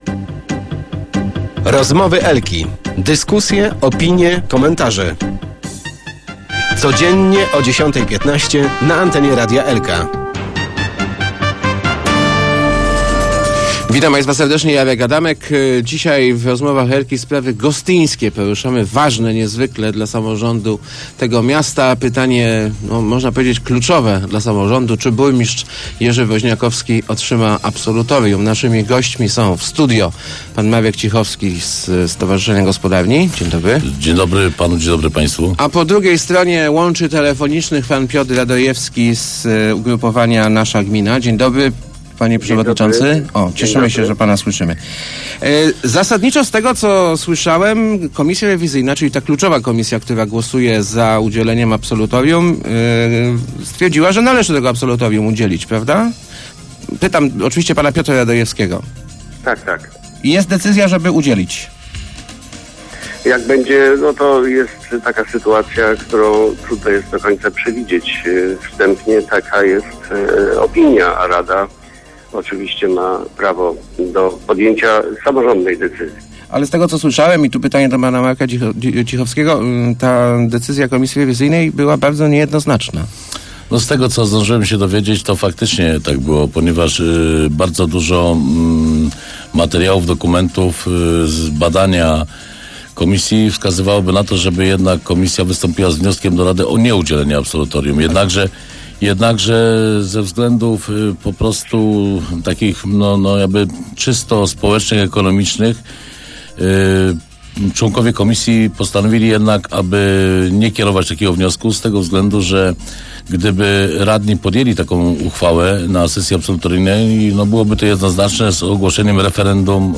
Mamy bardzo wiele zastrzeżeń do jego pracy - mówił w Rozmowach Elki radny Marek Cichowski z opozycyjnego ugrupowania Gospodarni.